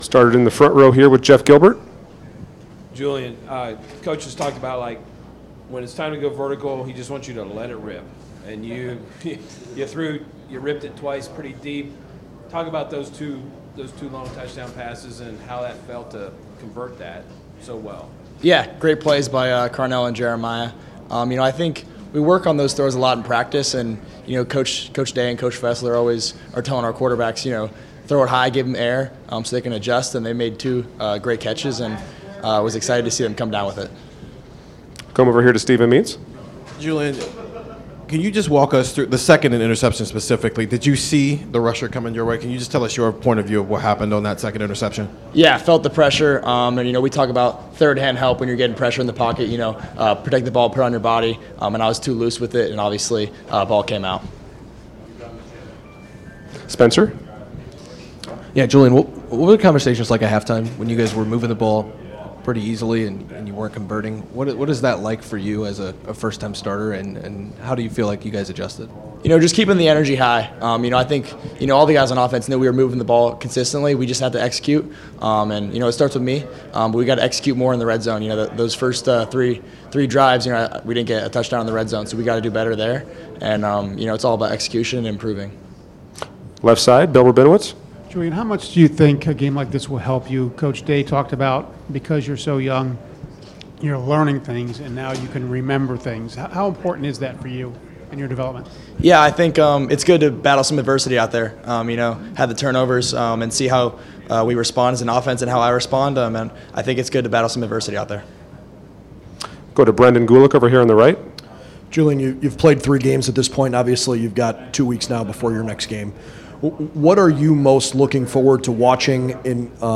Postgame Press Conference
Location:  Ohio Stadium; Columbus, Ohio